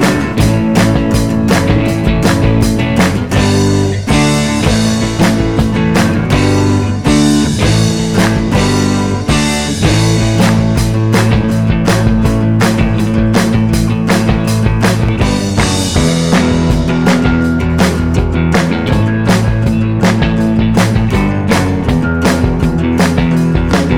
No Backing Vocals Rock 'n' Roll 3:38 Buy £1.50